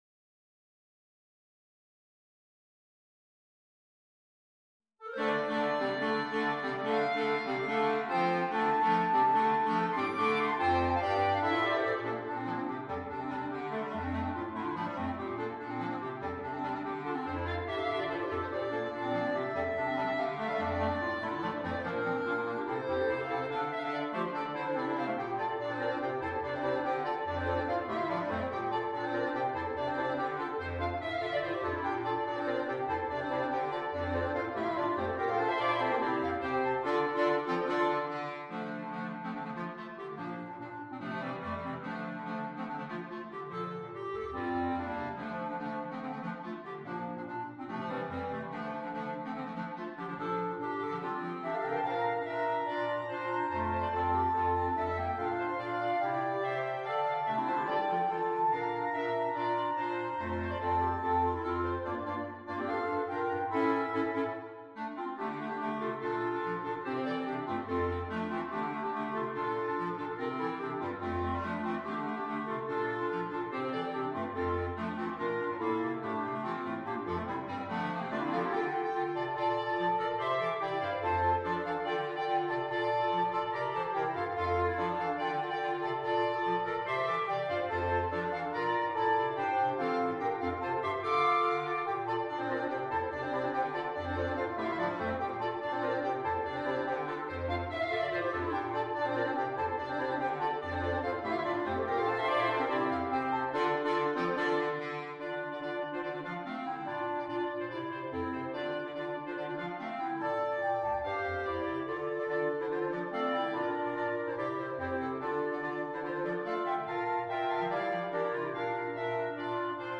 单簧管五重奏
风格： 流行